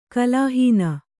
♪ kalāhīna